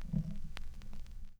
Record Noises
Record_End_5.aif